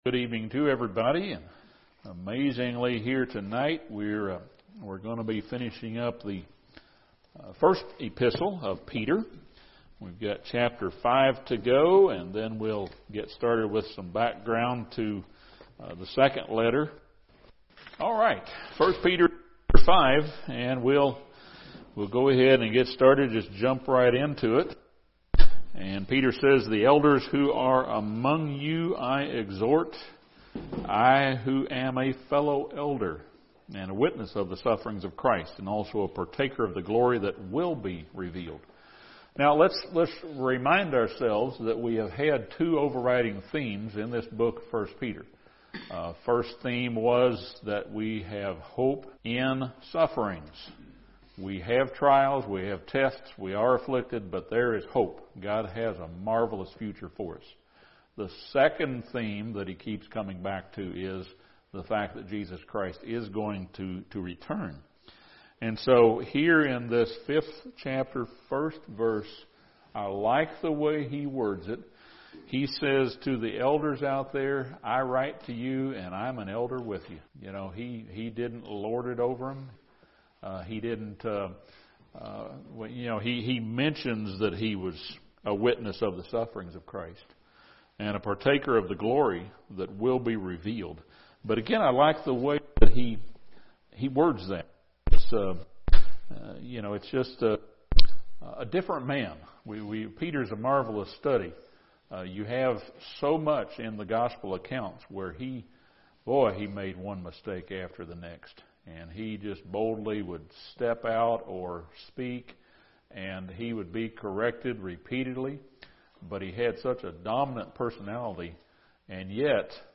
This Bible study surveys chapter 5 of 1 Peter, discusses background information to 2 Peter, and surveys chapter 1 of 2 Peter.